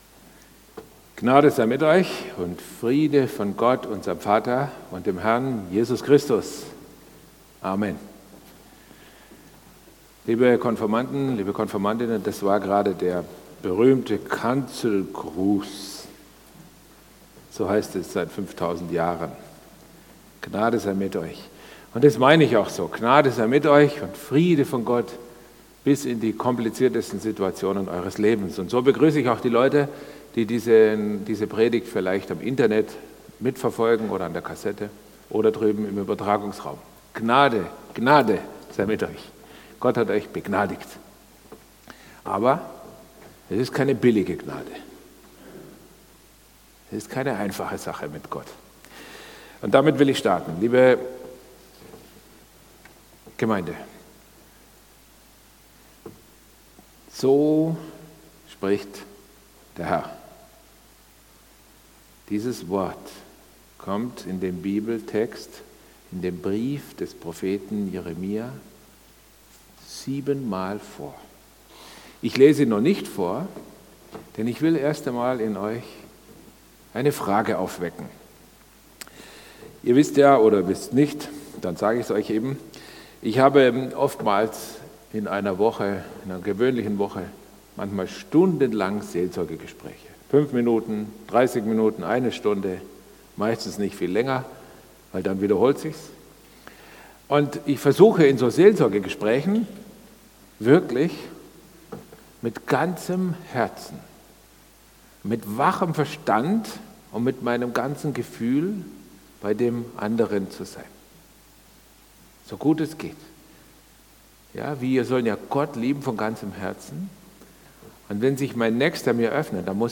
Predigt vom 21.10.2018